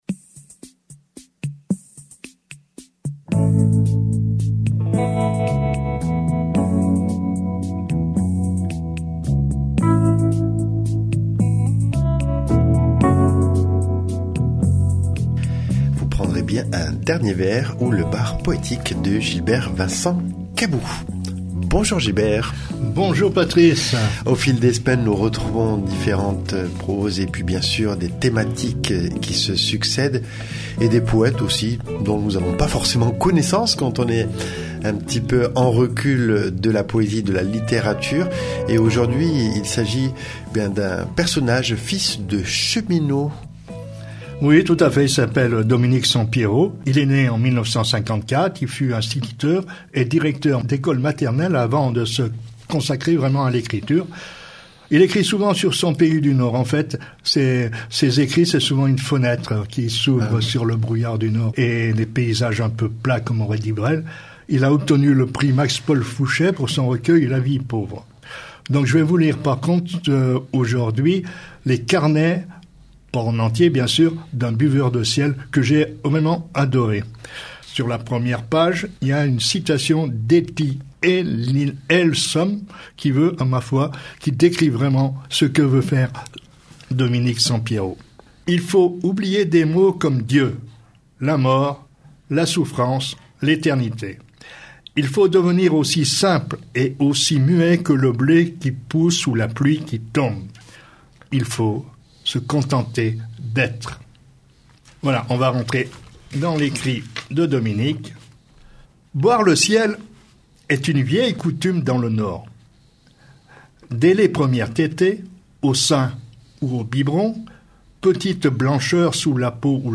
Au programme : lecture d’un poème par semaine accompagnée d’une petite biographie de l’auteur ou l’autrice. Le choix des poètes et poétesses se fait de manière totalement subjective, loin des locataires de Lagarde et Michard et souvent en prise avec l’actualité.